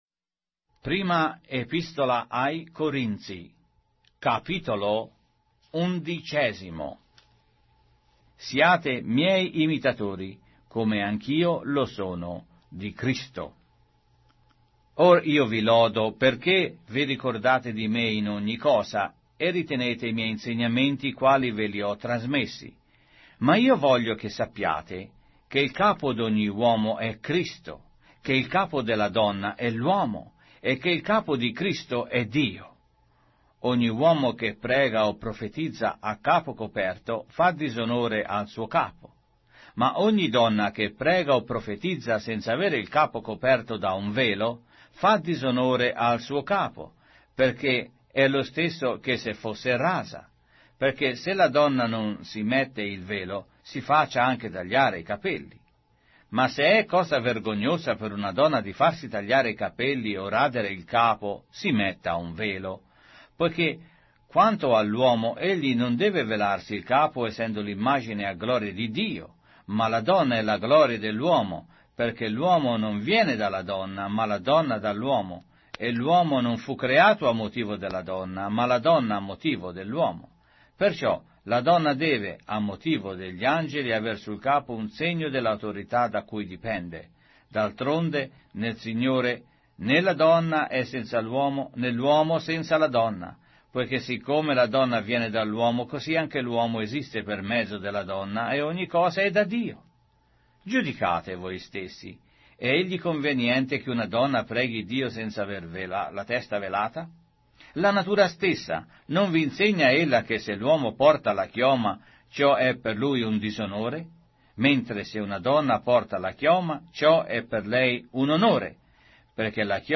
Sacra Bibbia - Riveduta - con narrazione audio - 1 Corinthians, chapter 11